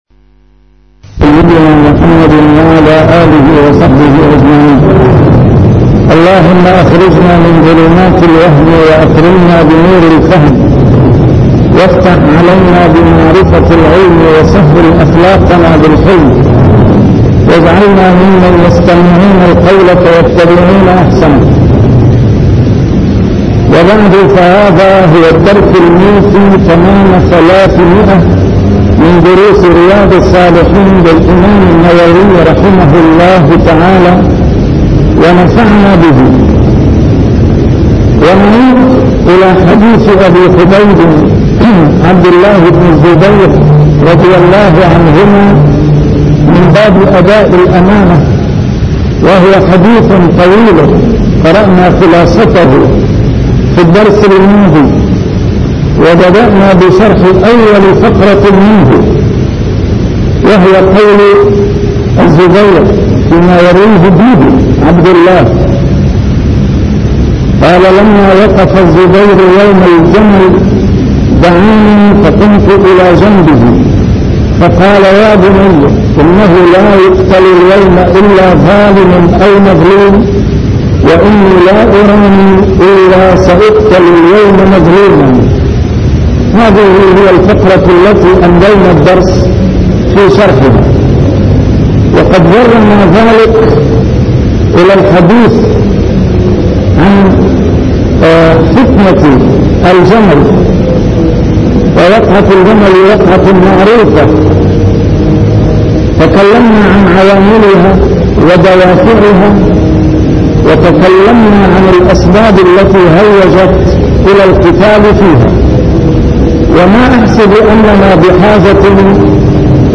A MARTYR SCHOLAR: IMAM MUHAMMAD SAEED RAMADAN AL-BOUTI - الدروس العلمية - شرح كتاب رياض الصالحين - 300- شرح رياض الصالحين: الأمر بأداء الأمانة